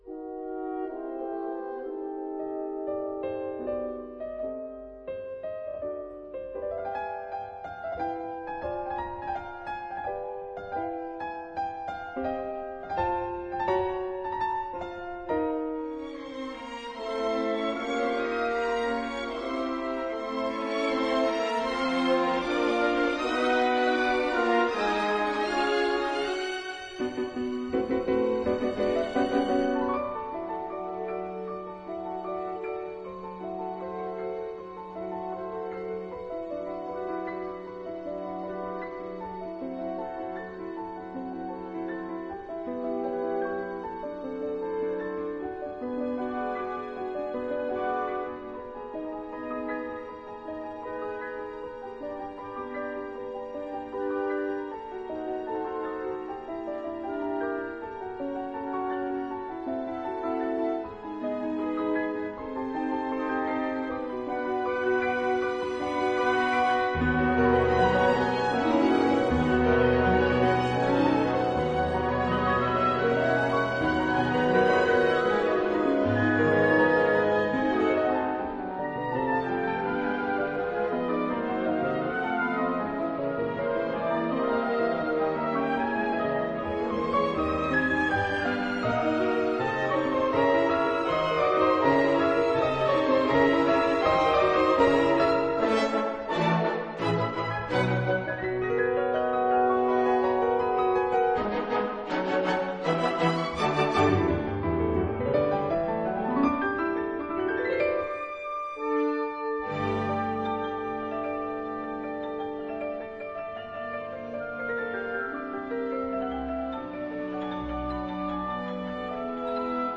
曲子相當微妙，流暢和平衡，沒有傳統形式的「悲愴」。
認識一下這位瑞士作曲家吧，他其實還蠻法國的。